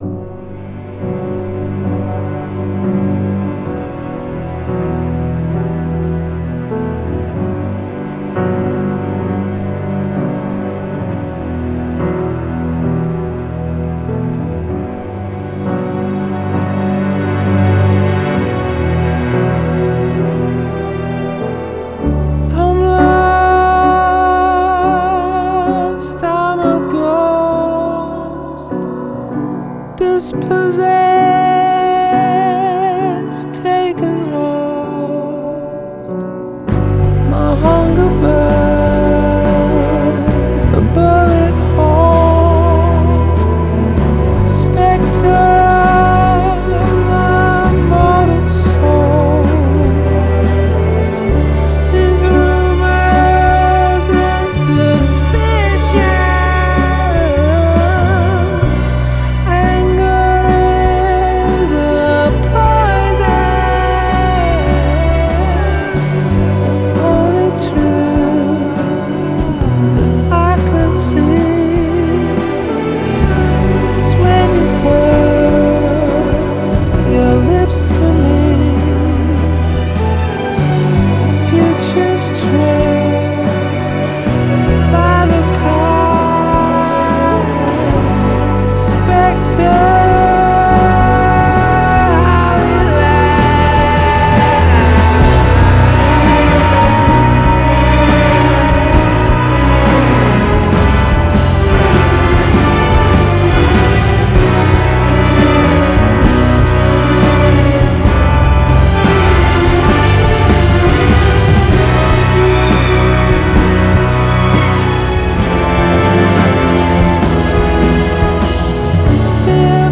it’s edgy and paranoid